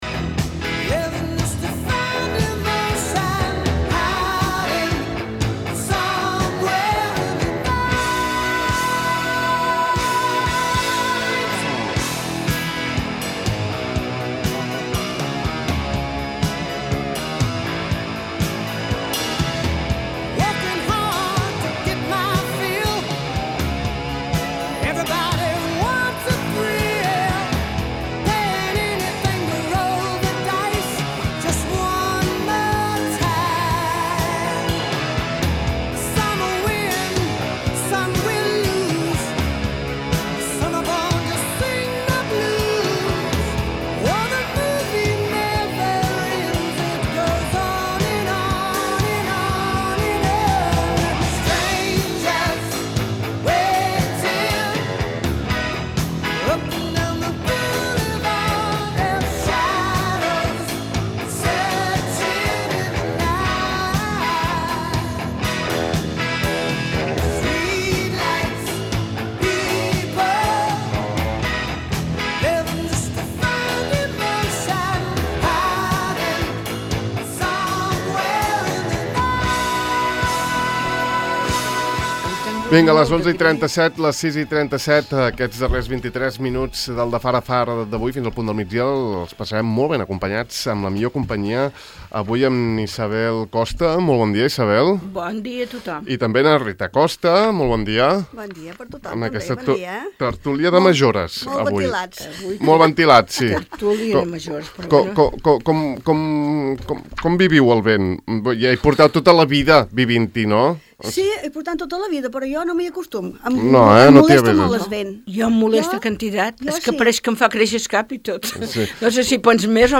L'arribada del cinema a Formentera a la tertúlia de majors